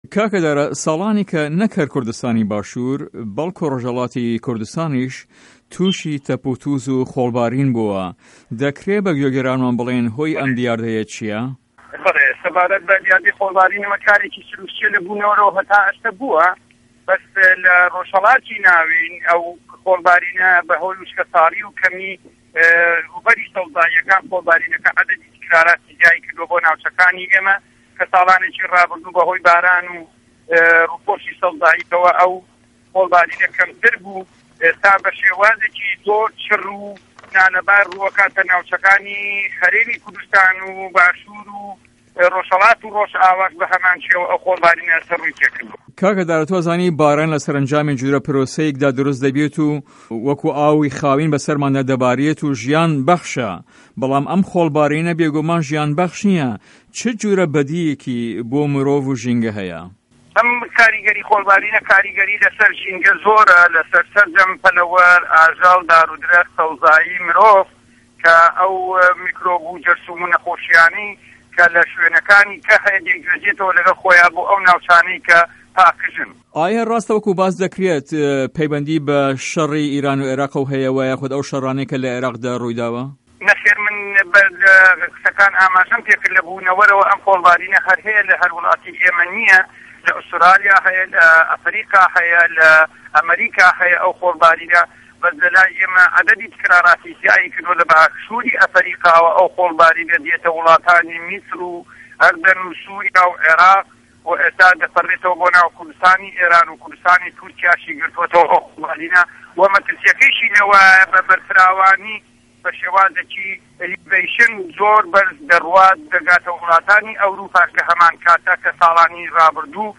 وتو وێژ